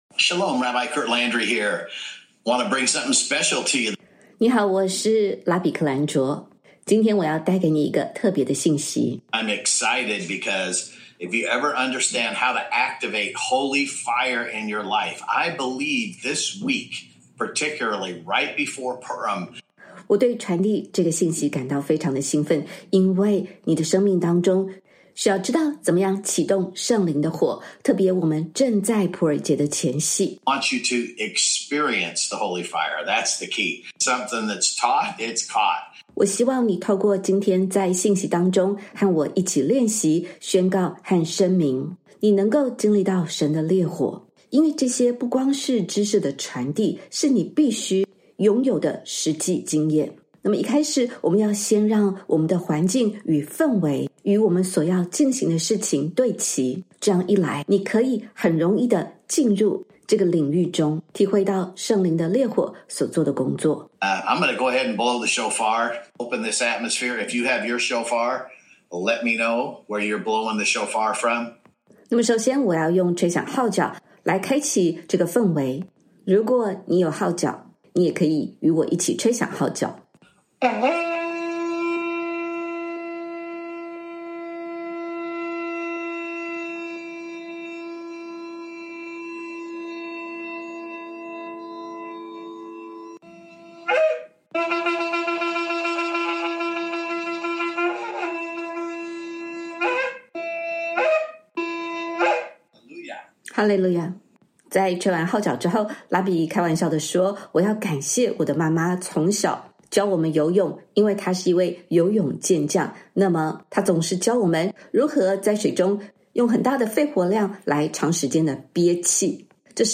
信息的开始，是拉比以号角声拉开序幕。